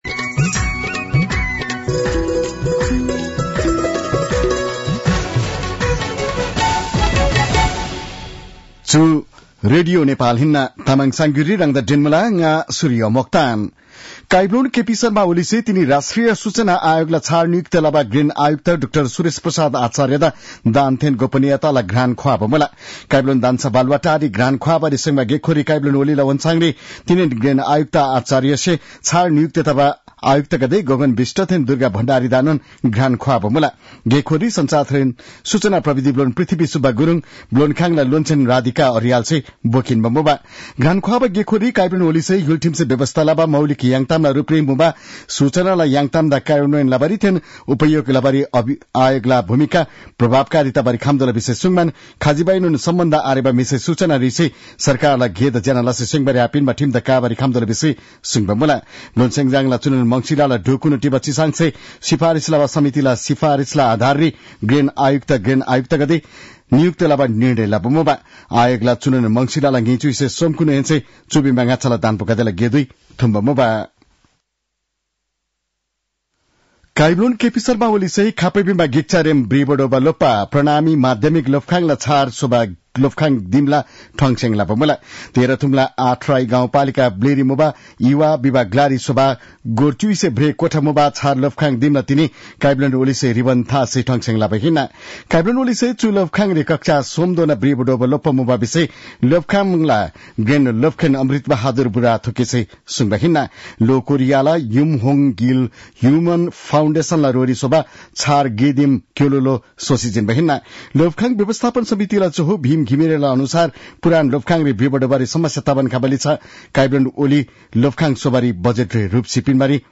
तामाङ भाषाको समाचार : २७ मंसिर , २०८१